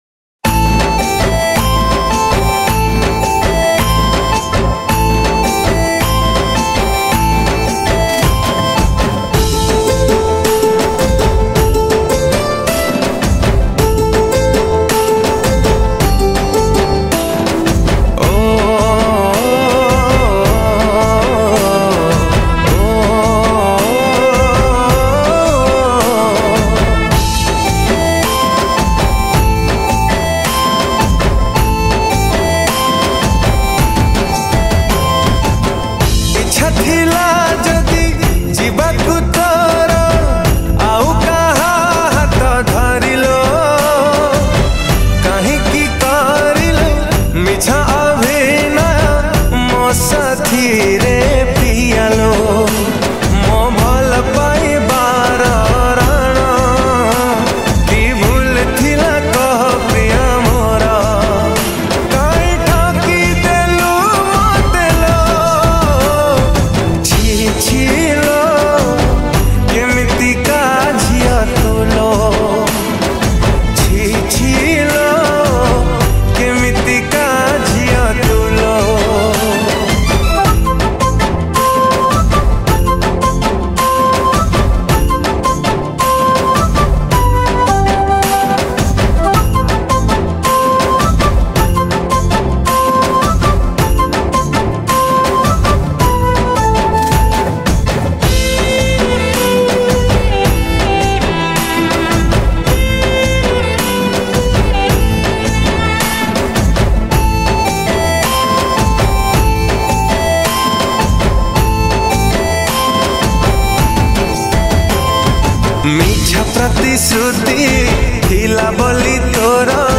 Official Studio Version